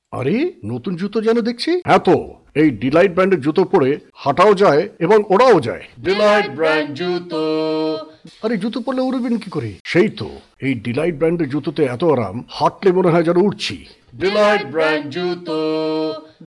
indisches engl.
Sprechprobe: eLearning (Muttersprache):